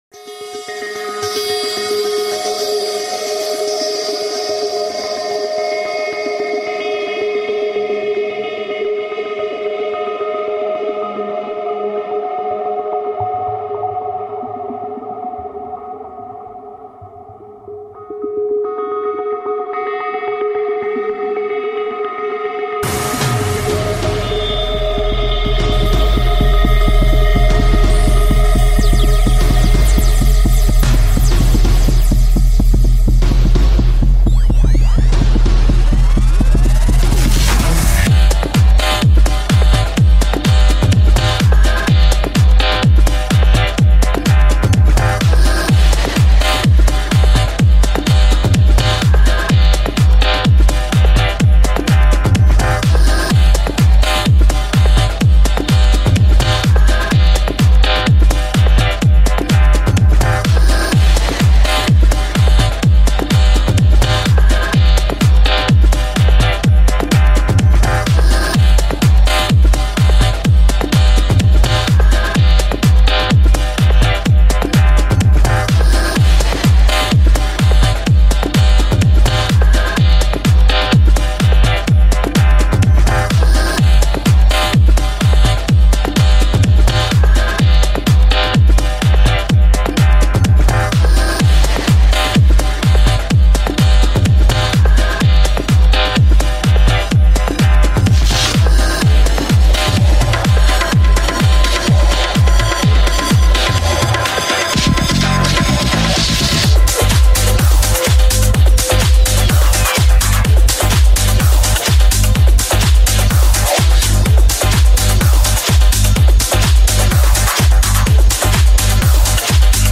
TECH HOUSE
BPM - 126